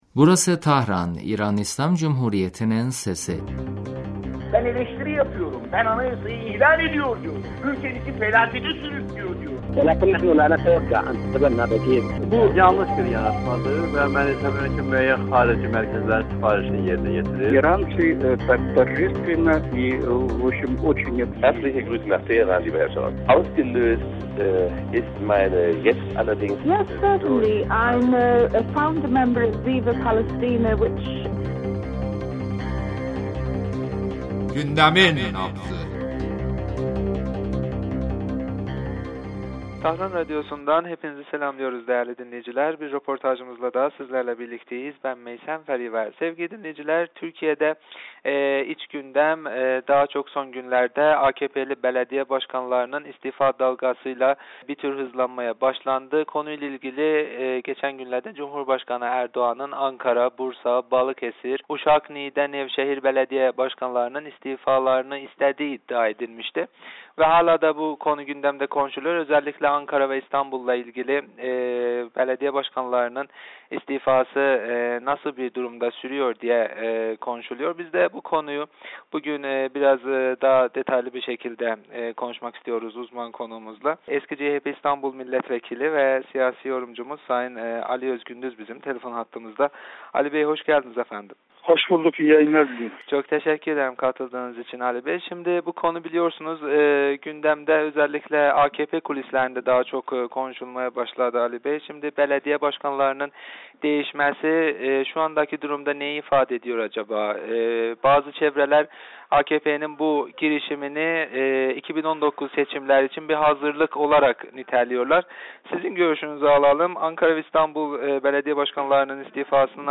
Eski CHP İstanbul milletvekili sayın Ali Özgündüz radyomuza verdiği demecinde Türkiye'de Belediye Başkanlarının istifa ettirilmeleri üzerinde görüşlerini bizimle paylaştı.